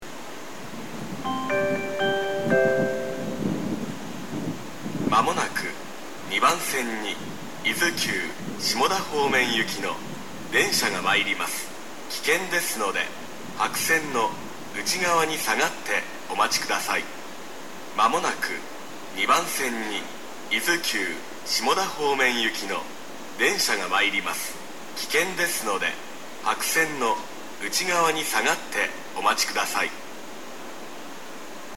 一部風が強い日に収録した音声があるので、風音が被っている音声があります。
（男性）
下り接近放送
Izukogen-2DSekkin.mp3